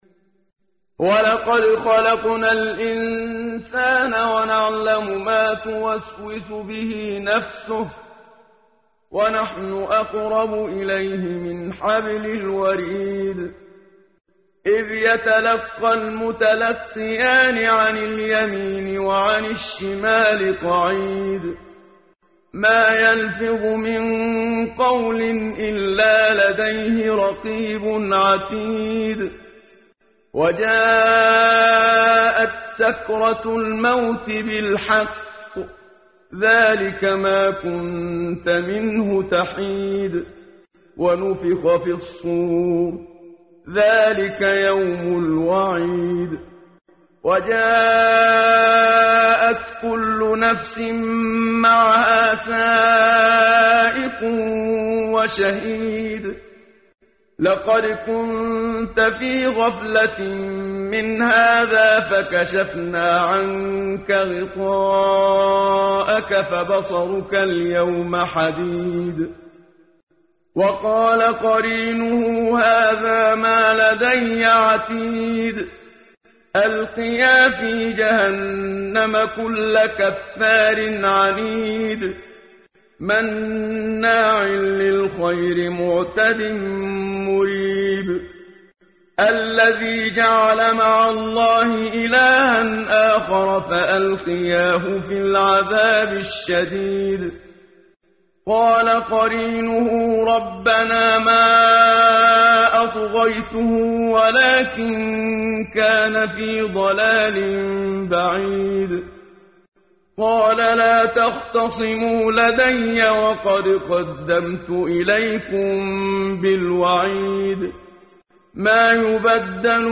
قرائت قرآن کریم ، صفحه 519 ، سوره مبارکه « ق» آیه 16 تا 25 با صدای استاد صدیق منشاوی.